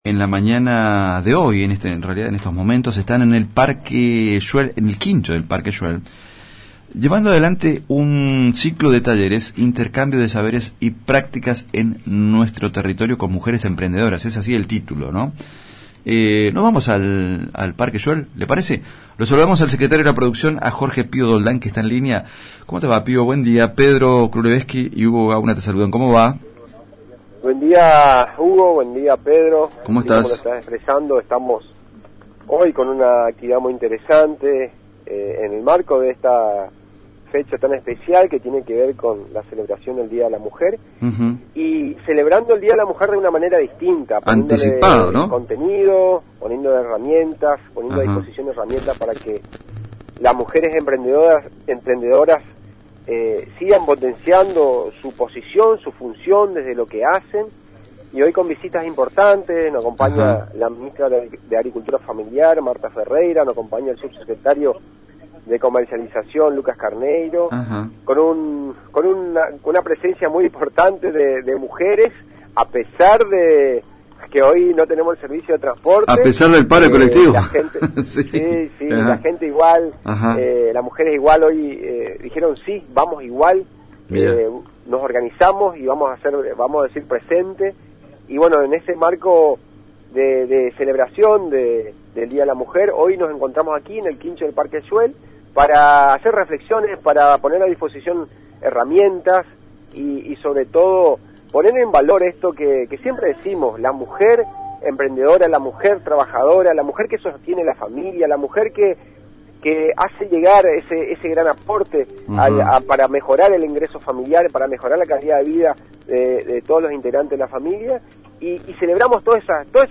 El secretario de Producción de la Municipalidad de Eldorado, Jorge «Pio» Doldán, en comunicación telefónica con ANG y Multimedios Génesis, comentó que la actividad tiene como eje celebrar a la mujer emprendedora, que trabaja, sostiene a la familia y hace llegar un aporte económico que mejora la calidad de vida de todos los integrantes de la familia.